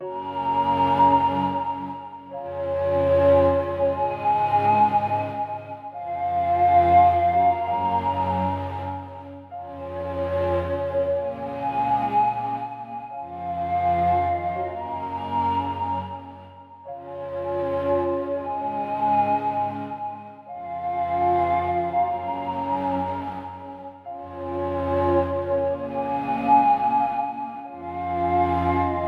Tag: 66 bpm Chill Out Loops Synth Loops 4.89 MB wav Key : Unknown Ableton Live